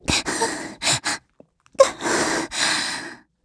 Gremory-Vox_Sad_kr.wav